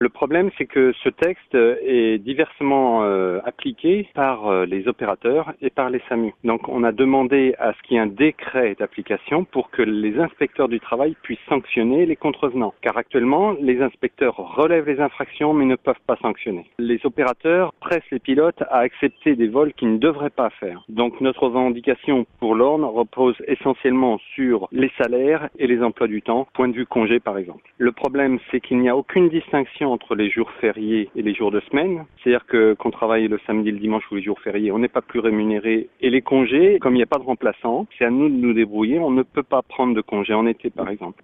greve-pilotes-helico-alencon-1.mp3